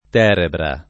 [ t $ rebra ]